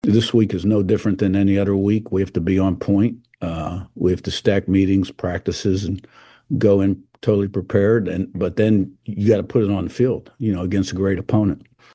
The two coaches in next Monday’s NCAA College Football National Championship game held their initial news conferences yesterday.